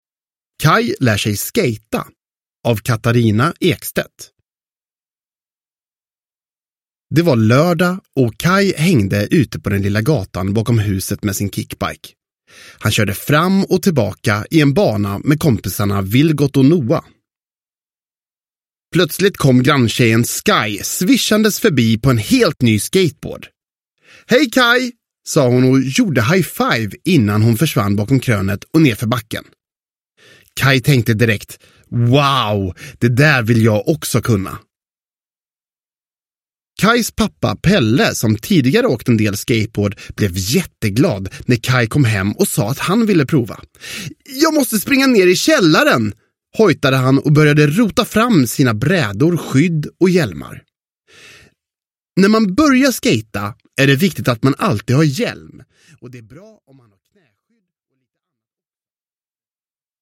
Kaj lär sig skejta – Ljudbok – Laddas ner